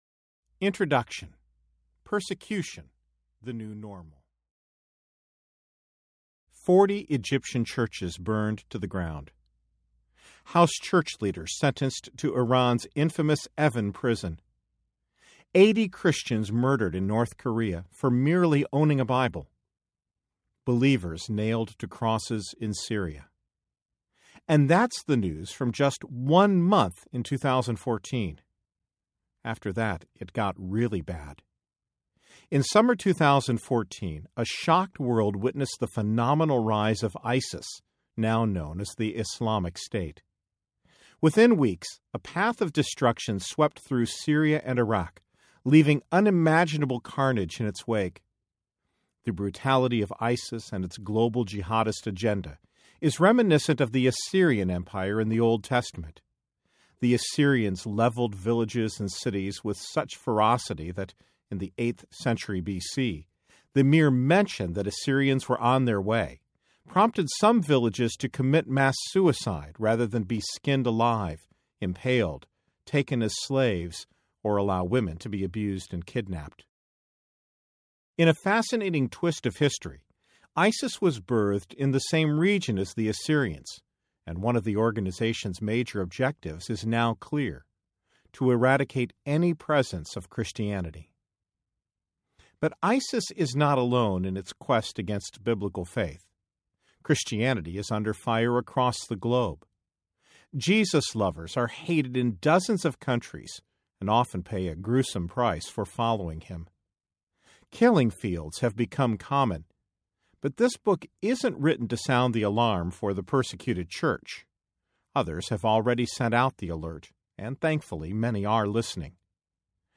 Killing Christians Audiobook
5.8 Hrs. – Unabridged